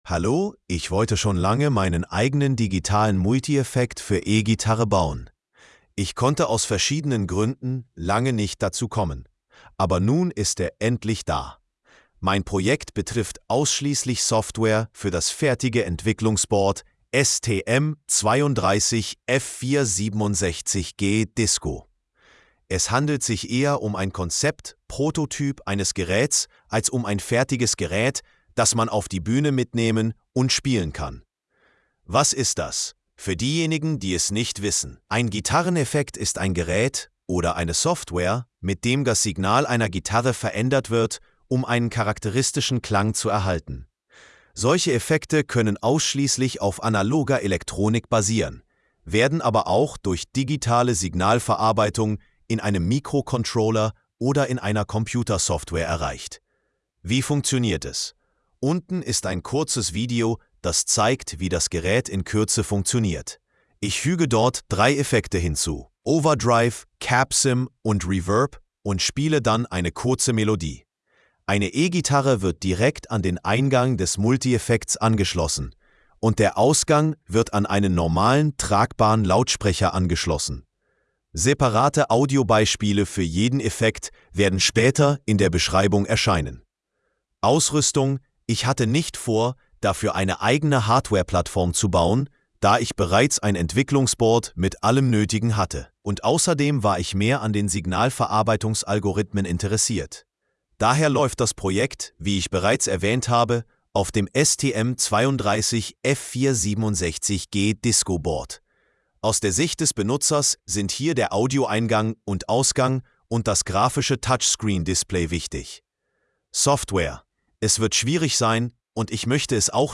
📢 Anhören (AI):